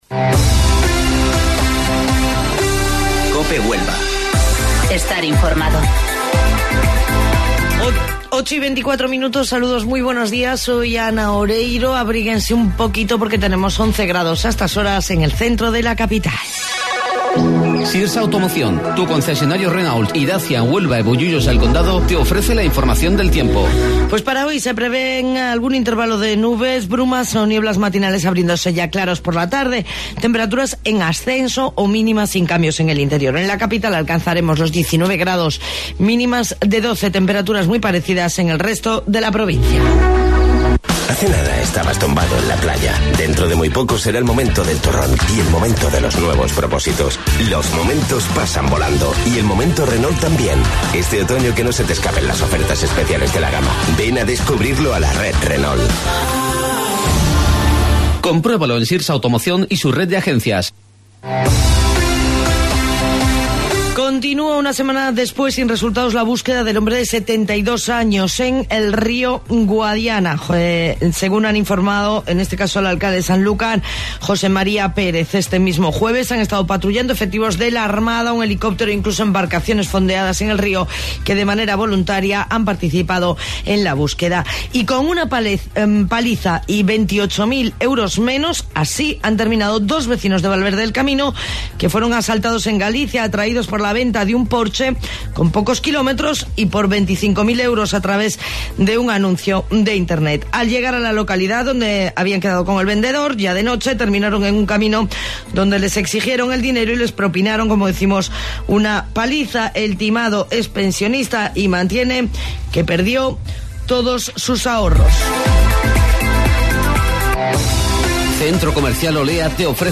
AUDIO: Informativo Local 08:25 del 29 de Noviembre